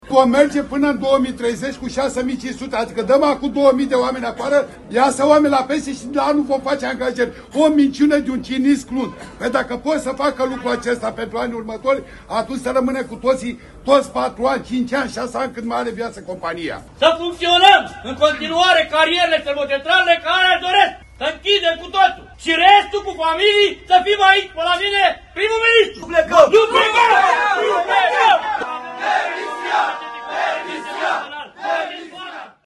„Este o minciună de un cinism crunt”, spune un angajat